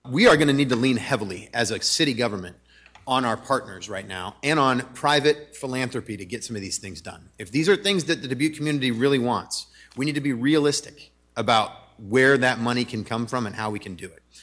Before the council voted Dubuque Mayor Brad Cavanaugh said he supports the plan, but said he feels due what he called constricting levels of state and national government, funding may be hard to come by.